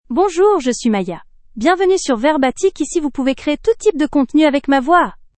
Maya — Female French (France) AI Voice | TTS, Voice Cloning & Video | Verbatik AI
Maya is a female AI voice for French (France).
Voice sample
Listen to Maya's female French voice.
Maya delivers clear pronunciation with authentic France French intonation, making your content sound professionally produced.